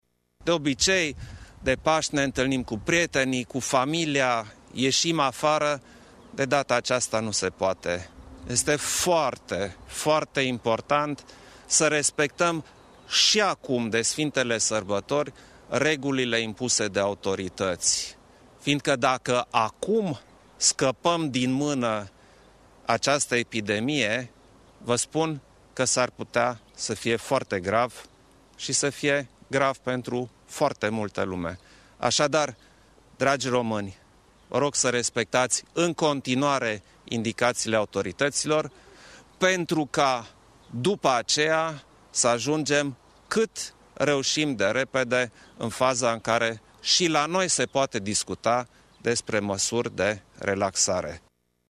Șeful statului a precizat că nu va merge la biserică și îi îndeamnă pe toți cetețenii să îi urmeze exemplul: